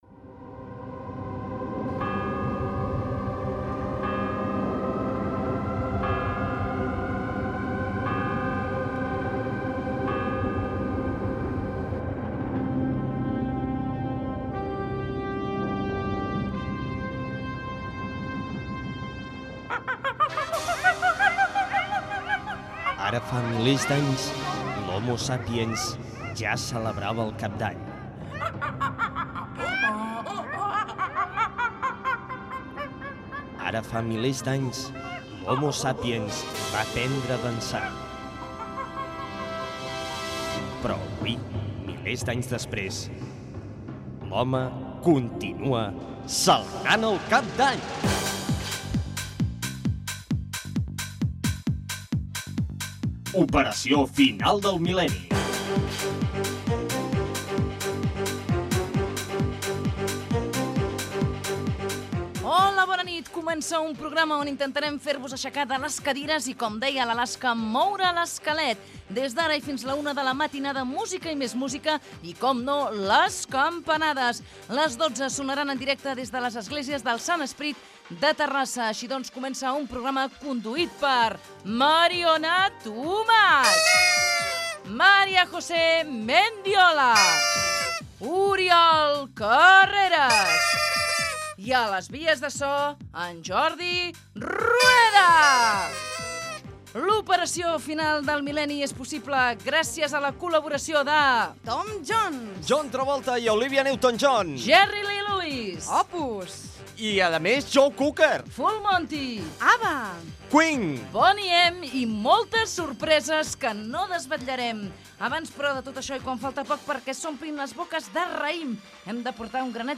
Especial cap d'any. Careta del programa, presentació, equip, grups musicals que es programaran, "Cançó dels adéus", explicació de les campanades, prepració del raïm, campanades des de l'església del Sant Esperit de Terrassa.
Entreteniment